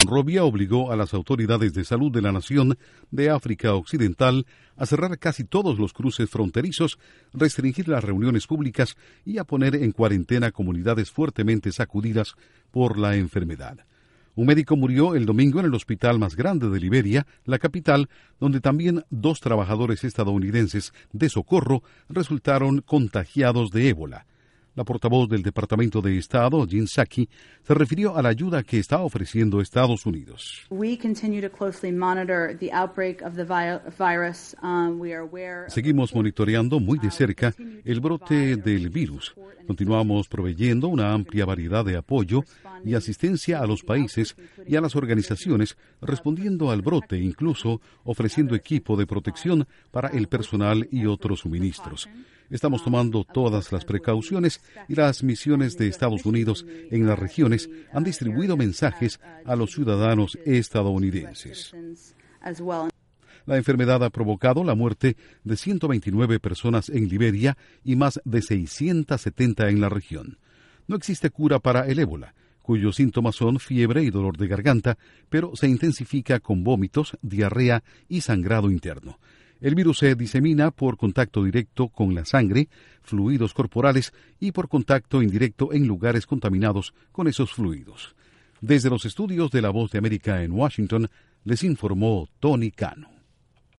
Intro: Estados Unidos observa con atención un brote de Ebola. Informa desde los estudios de la Voz de América en Washington